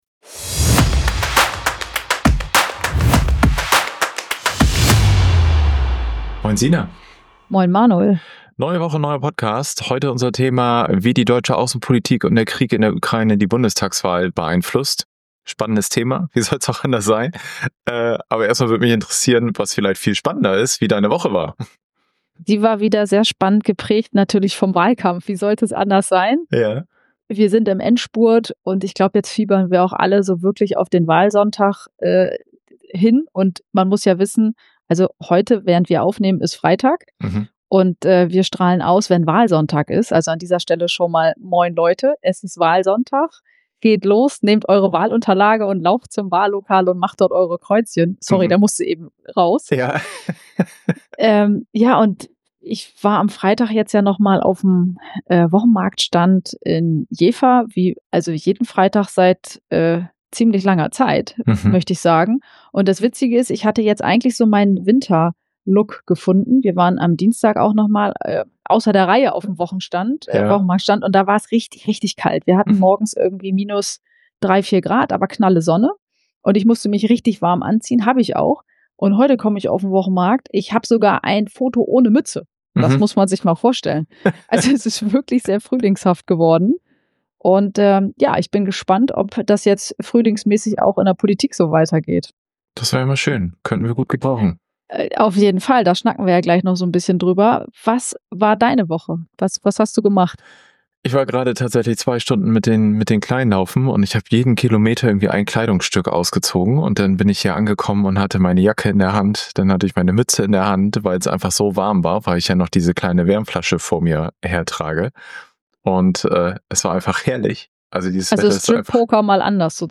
Unser Ziel ist es, Politik verständlich und greifbar zu machen – immer mit einem lockeren, aber informativen Ton.